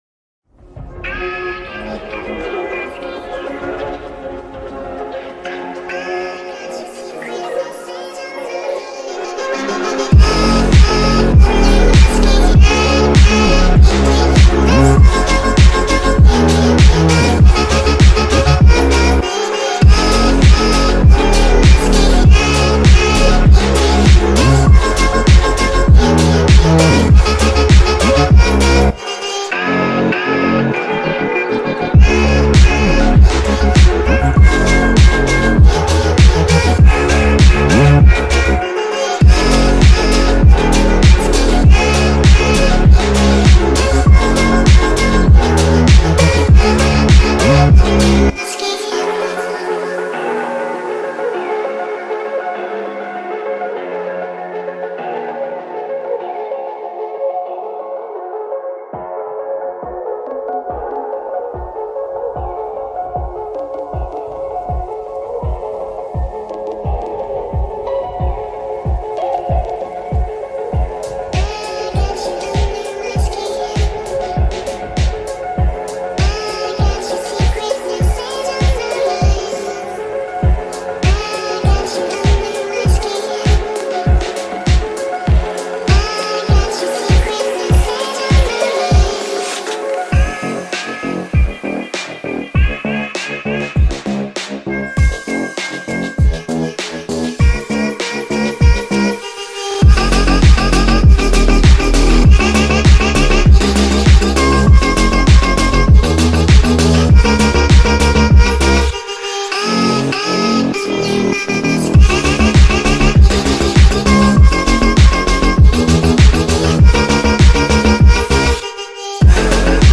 TikTok Remix Version